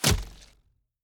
Bow Attacks Hits and Blocks
Bow Impact Hit 1.wav